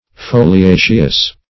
Foliaceous \Fo`li*a"ceous\, a. [L. foliaceus, fr. folium leaf.]